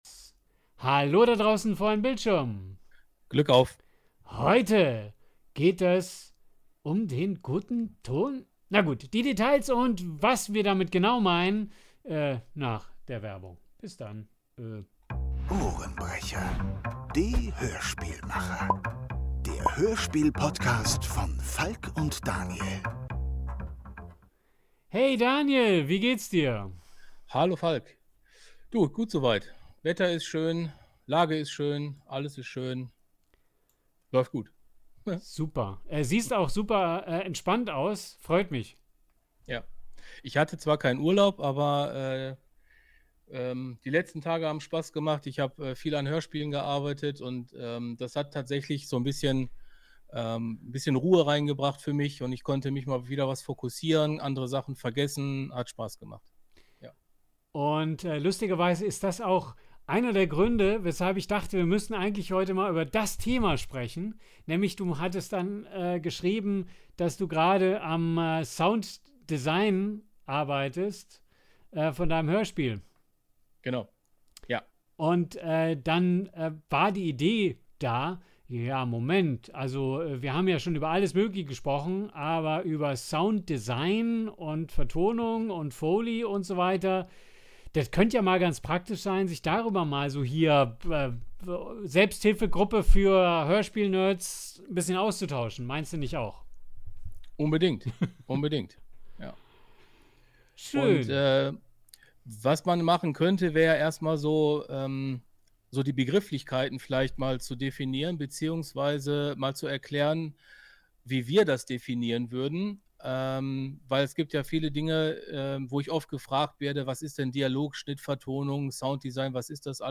Diese Folge ist kein Tutorial – sondern ein offenes Gespräch zweier Audio-Macher, die sich mit Leidenschaft und Humor durch ihre eigenen Projekte hören und dabei ehrlich zeigen, was sie bewegt, inspiriert oder auch mal frustriert.